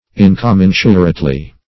In`com*men"su*rate*ly, adv. --
incommensurately.mp3